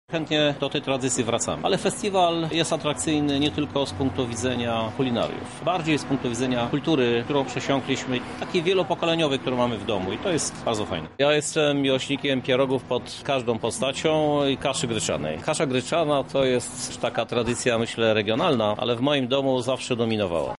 Inicjatywę popiera prezydent miasta, Krzysztof Żuk, prywatnie miłośnik lokalnej kuchni: